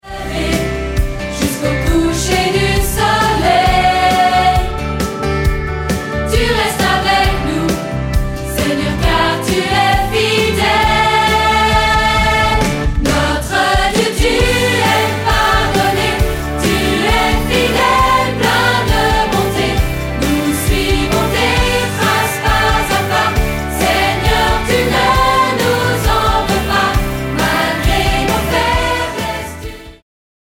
• Chants de louange pour églises, familles et autres
• Chorale et solistes